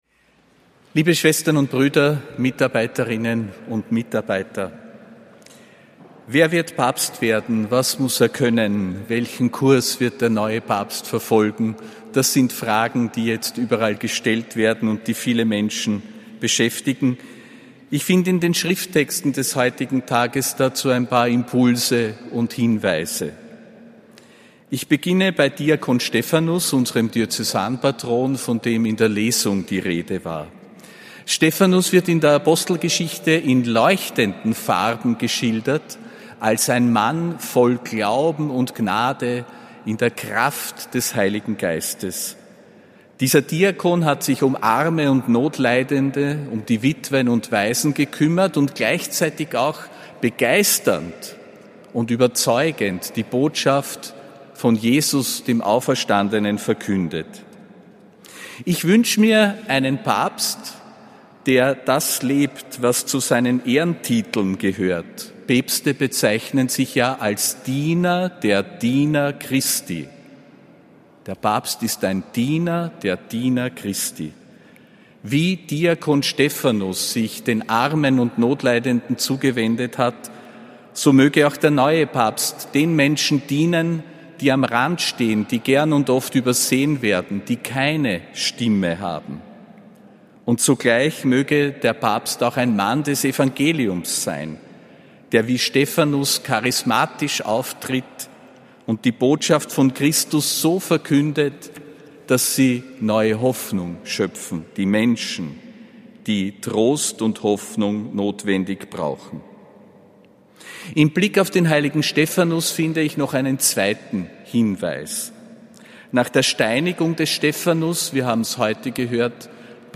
Predigt zum Mitarbeitergottesdienst (7. Mai 2025)
Predigt des Apostolischen Administrators Josef Grünwidl zum